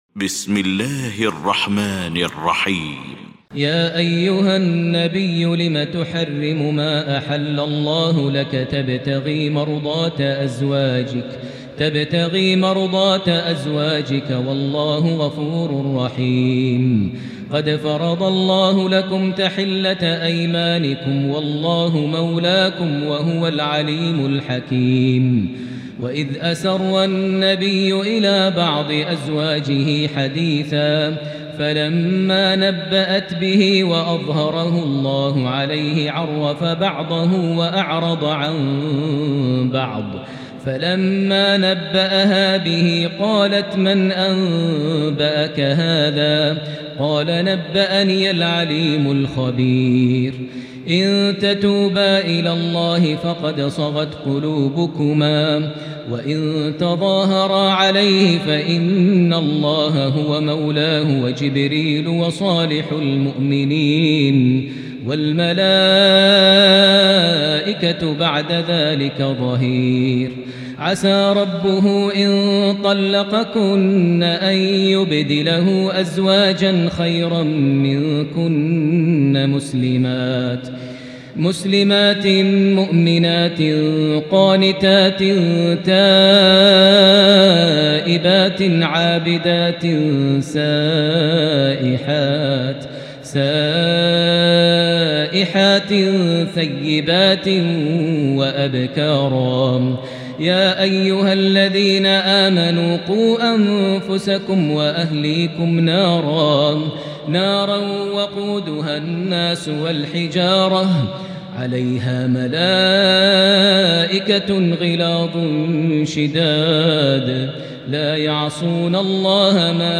المكان: المسجد الحرام الشيخ: فضيلة الشيخ ماهر المعيقلي فضيلة الشيخ ماهر المعيقلي التحريم